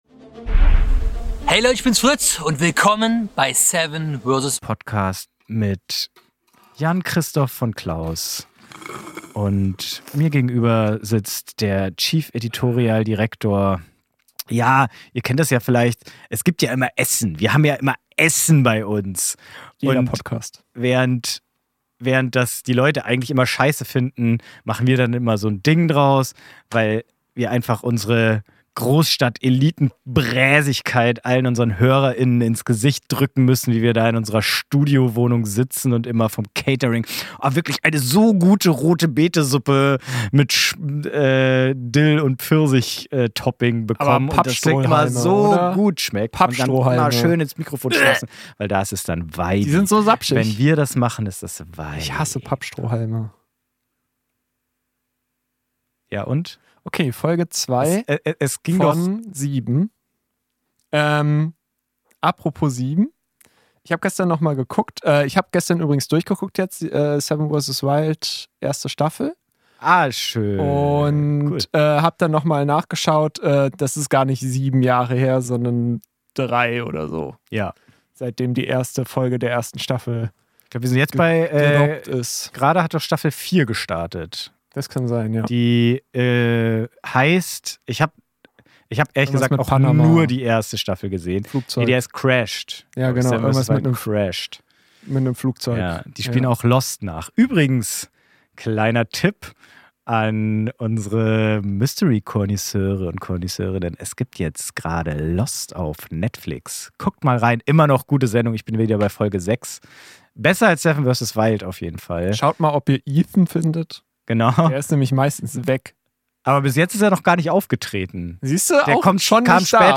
unterhalten sich unsere sympathischen Hosts